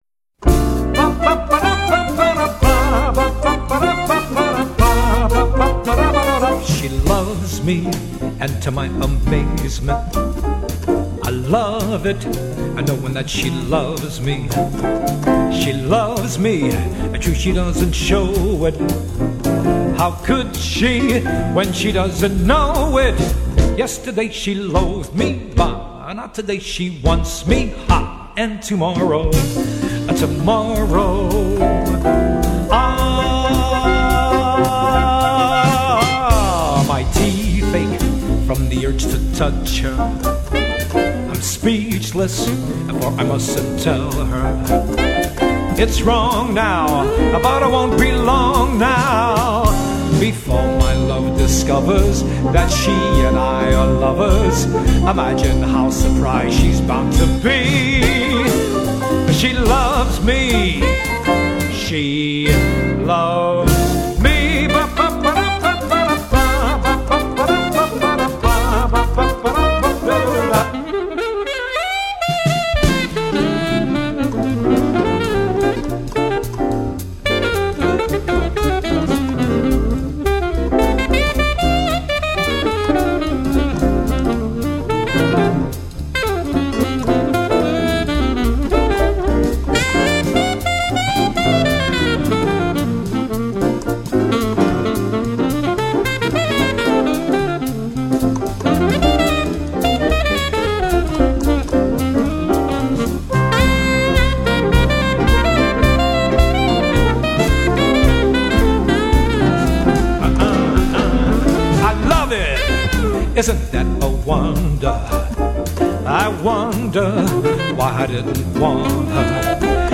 1963   Genre: Musical   Artist